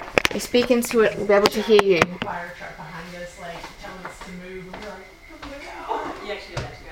EVP 3 – Due to all of us hearing scratching downstairs this audio was captured on the additional audio recorder placed downstairs.